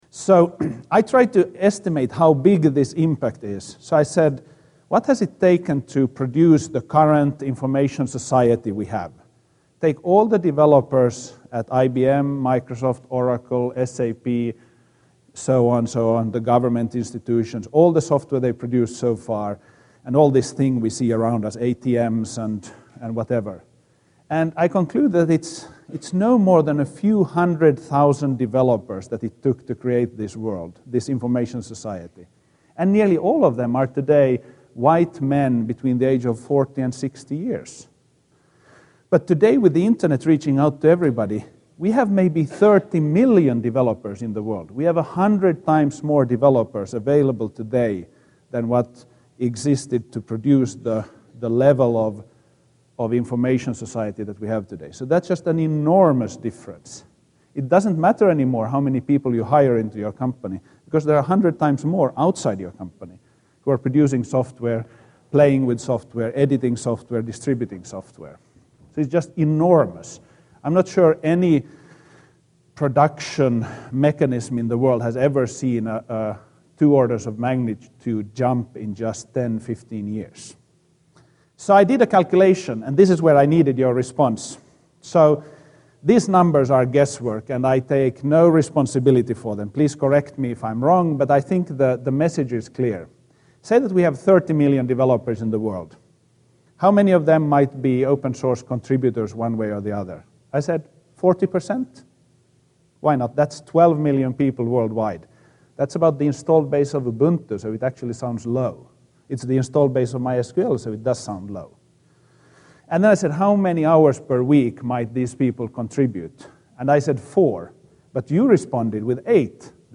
excerpt from his talk at the 2007 Ubuntu Conference, MySQL CEO Marten Mickos estimates the productive power of the aggregate community of open source developers. His estimate is that there are 1.2 million full-time equivalent open source developers -- about 17 times the size of Microsoft.